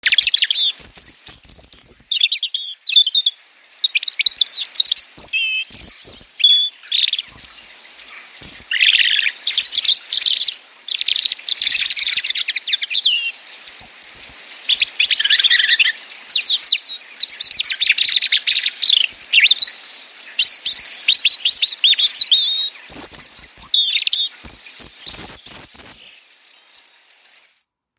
Măcăleandrul (Erithacus rubecula)
• Are pieptul portocaliu-aprins și un tril melodios, care te înveselește imediat.
Ascultă cântecul măcăleandrului!